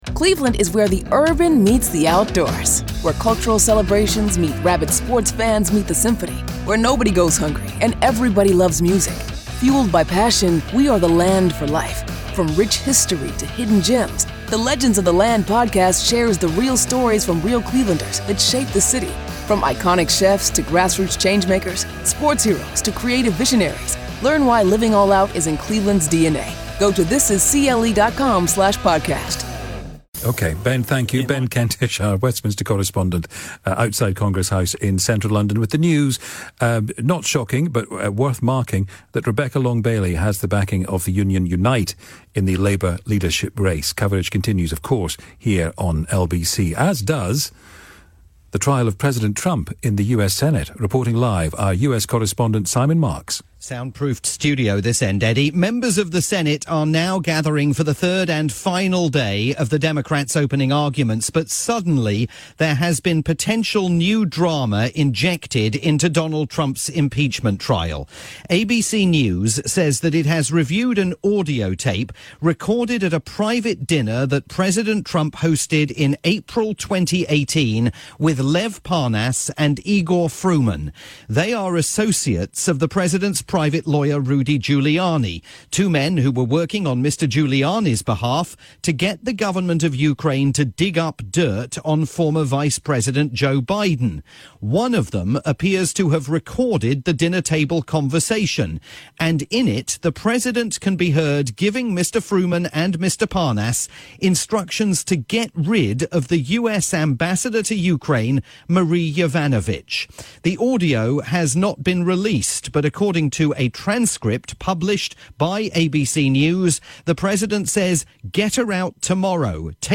live report on the latest developments in Washington for LBC's nightly drivetime programme hosted by Eddie Mair.